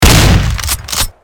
جلوه های صوتی
دانلود صدای تفنگ 7 از ساعد نیوز با لینک مستقیم و کیفیت بالا